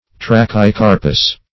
Search Result for " trachycarpous" : The Collaborative International Dictionary of English v.0.48: Trachycarpous \Tra`chy*car"pous\, a. [Gr.